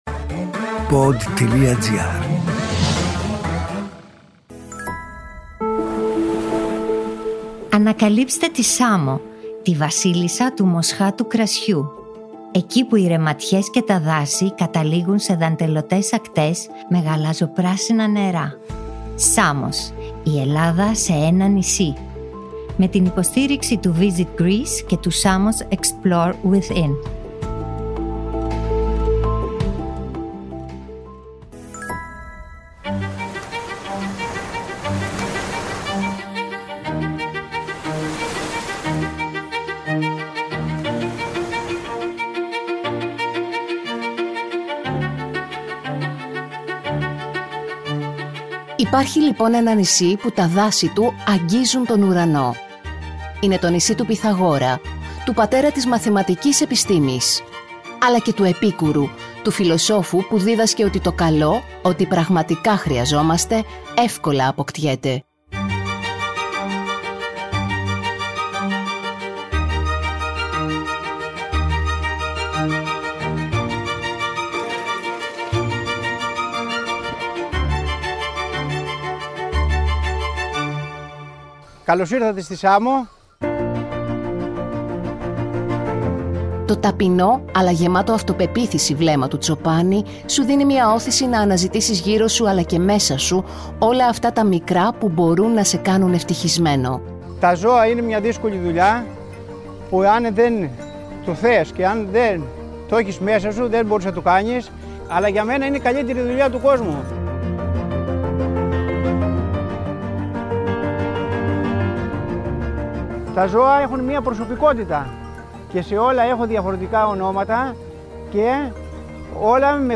Δάση, αμπελώνες, ορεινά μονοπάτια και χωριά δίχως αυτοκίνητα. Σε αυτό το επεισόδιο ξεναγοί μας είναι ένας τσοπάνης, η ιδιοκτήτρια ενός ράντσου αλόγων, ντόπιοι οινοποιοί, παραγωγοί χαρουπόμελου και νέοι που αναδεικνύουν τη σαμιώτικη γαστρονομία.